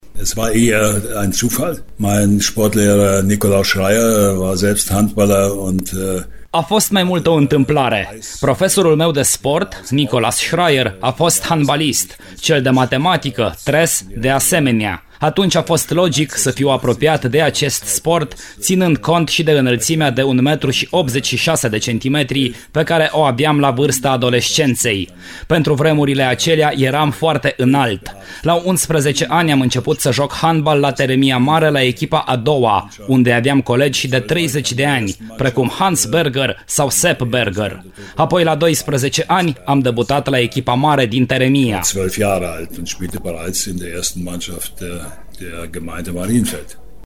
AUDIO / ”Hansi” Schmidt, invitat la Arena Radio!
Ascultați mai jos, un fragment din primul episod, care va fi mâine, între orele 11 și 12, la Arena Radio; e vorba de începuturile ca handbalist: